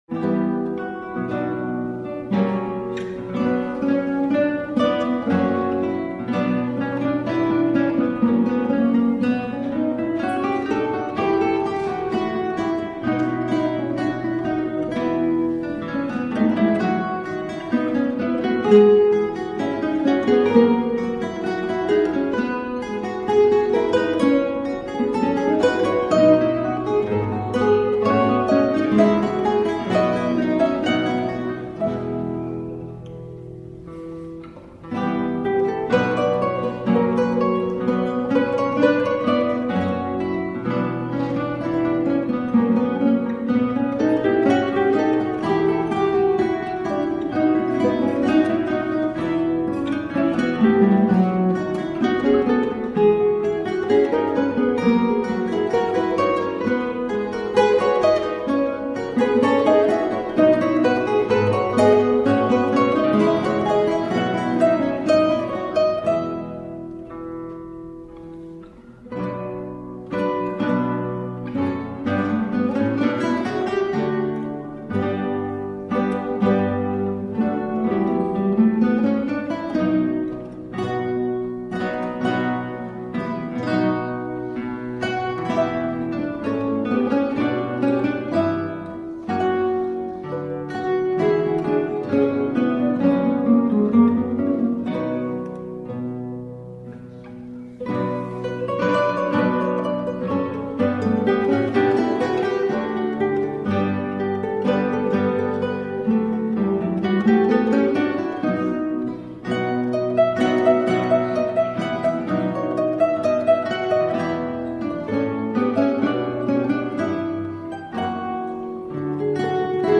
Il s'agit d'un arrangement personnel pour 4 guitares, interprété et enregistré en concert le 14 avril dernier. La prise de son n'est pas terrible terrible ce qui donne un effet de déséquilibre entre les voix.
ce sont bien 4 guitares qui jouent.
Voili voilou en bref : effectivement, le fait de jouer certaines guitares avec des capos donne ce timbre plus "pincé", se rapprochant du luth. L'effet est encore plus accentué du fait du léger "désaccordement" d au capo (les luths de l'époque ayant leurs cordes en boyau implique que ce n'était jamais vraiment accordé pile poil).
J'ai beaucoup aimé, c'est vraiment de la musique d'ensemble, et des voix se détachent sans cesse dans des mouvements très fluides, merci et bravo!